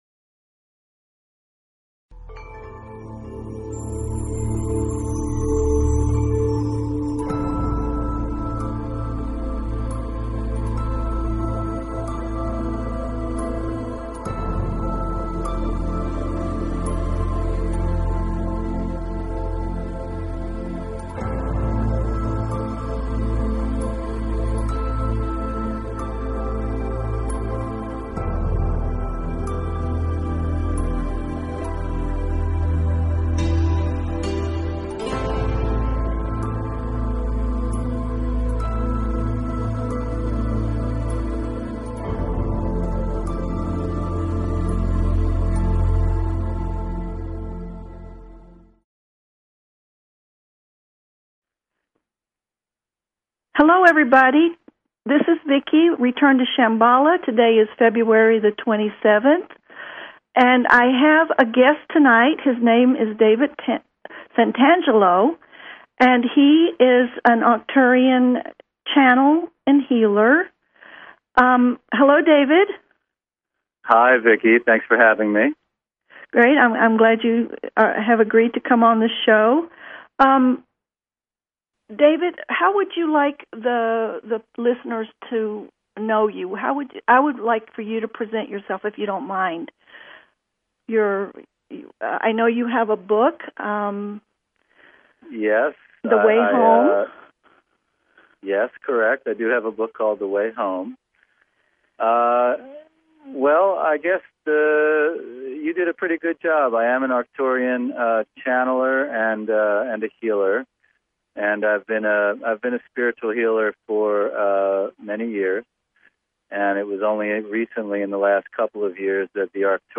Talk Show Episode, Audio Podcast, Return_to_Shamballa and Courtesy of BBS Radio on , show guests , about , categorized as